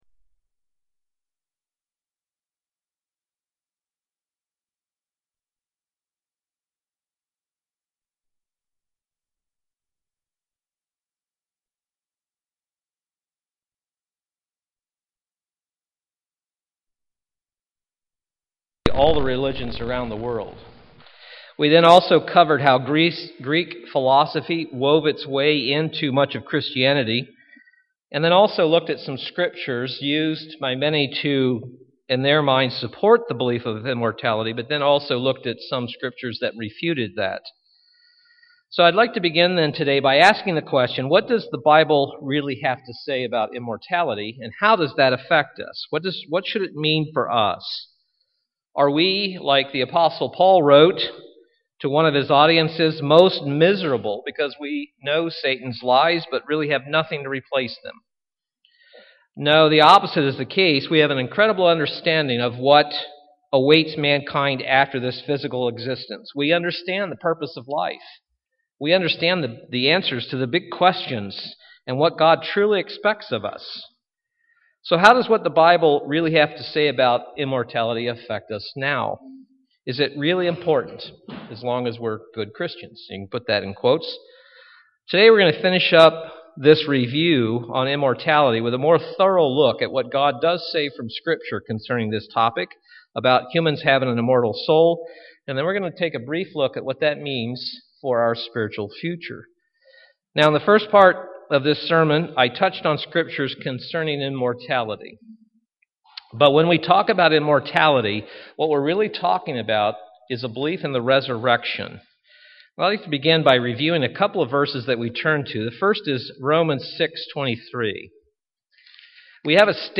Last Sabbath, in the first part of this sermon series on the Doctrine of the Immortal Soul, we took a look at the common belief in some type of immortality in virtually all religions around the world.